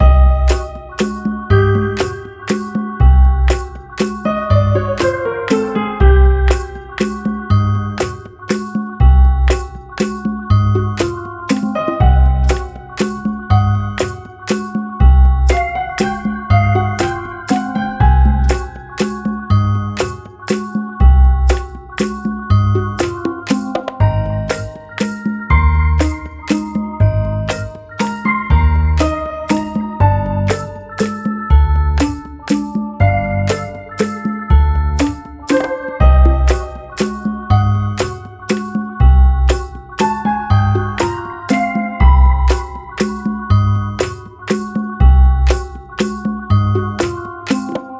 ゆったりした楽曲
【イメージ】”迷”探偵、へろへろ など